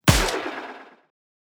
Revovler.wav